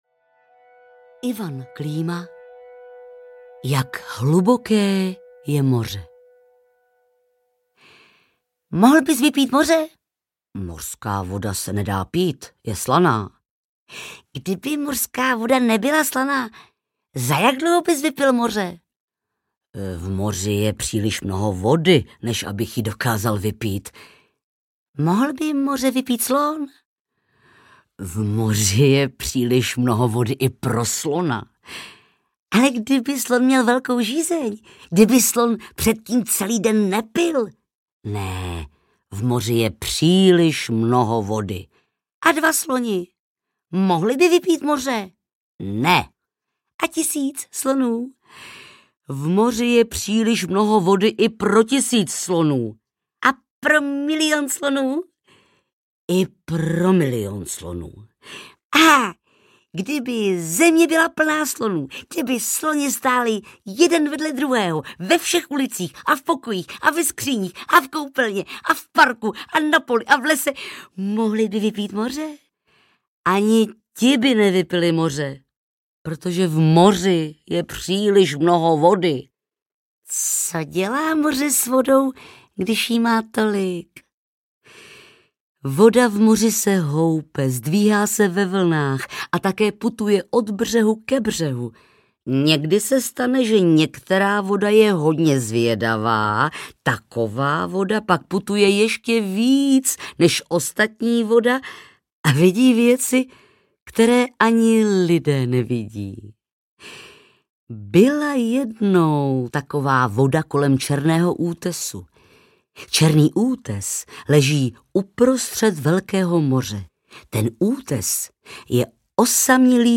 Jak hluboké je moře audiokniha
Ukázka z knihy
• InterpretBára Hrzánová